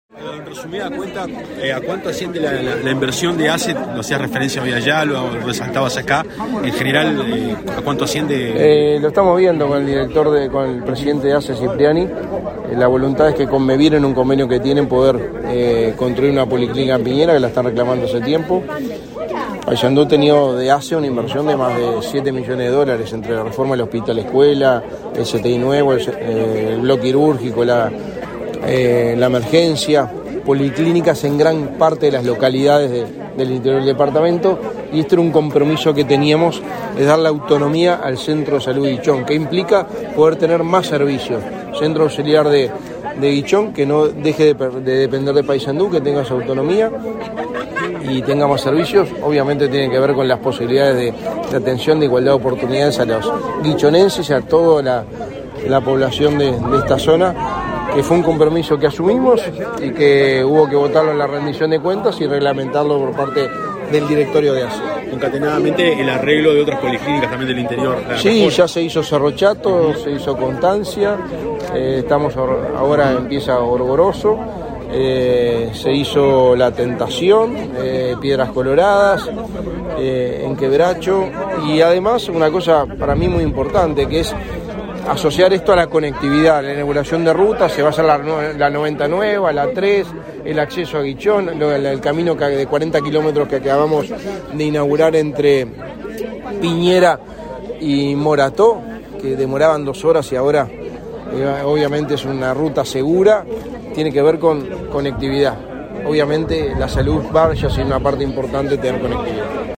Declaraciones a la prensa del secretario de la Presidencia, Álvaro Delgado
Luego, realizó declaraciones a la prensa.